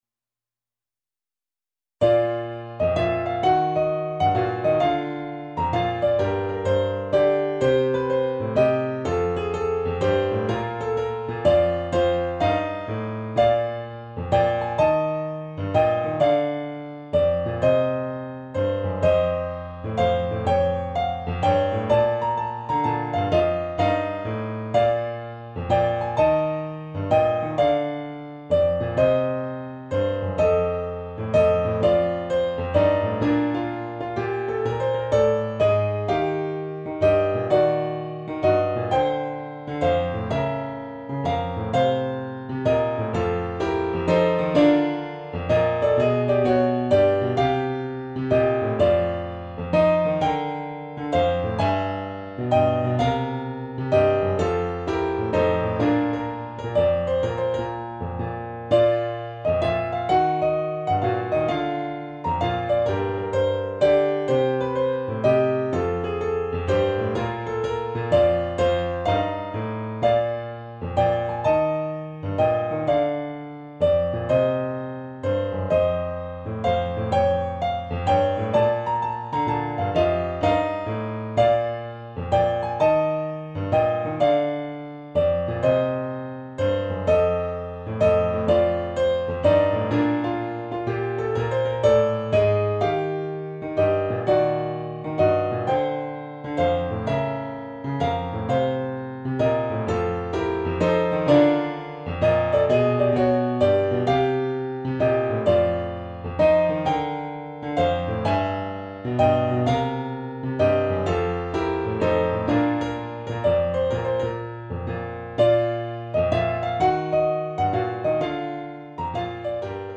【ピアノ楽譜付き】